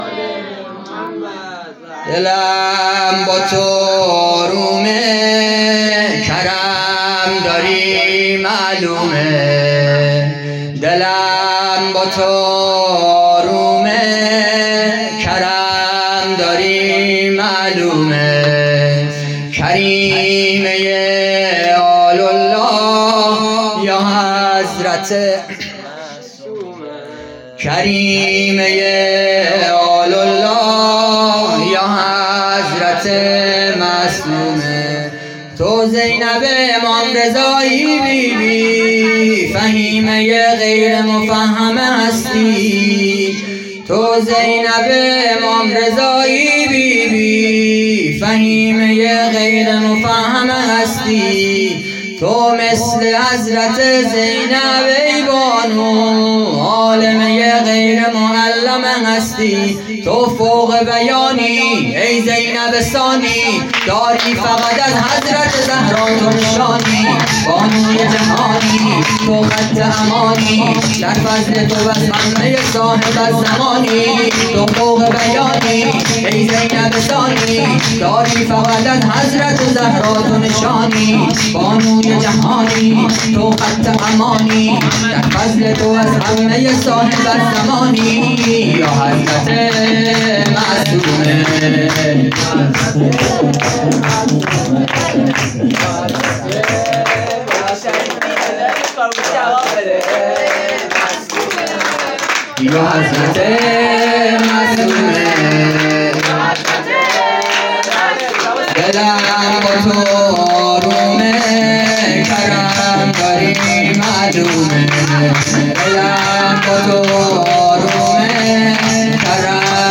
[فایل صوتی] - [سرود] - [حضرت معصومه(س)] - دلم با تو آرومه....